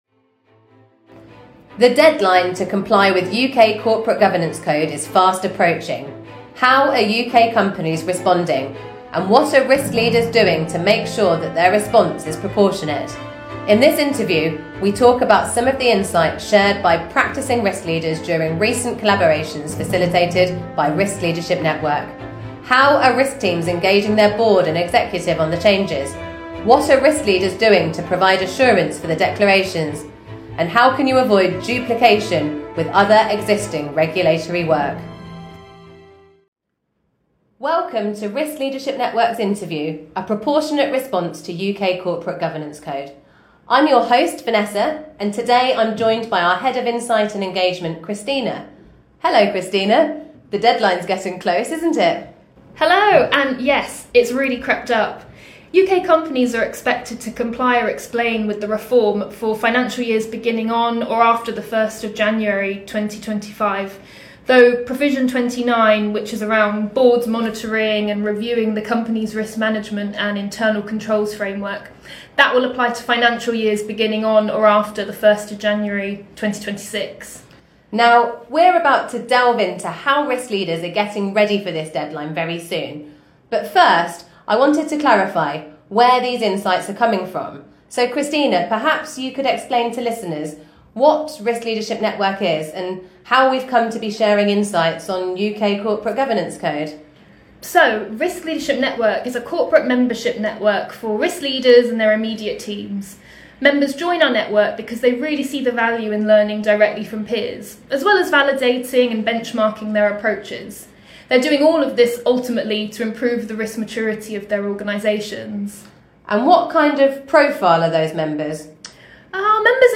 Interview: A proportionate response to UK Corporate Governance Code reform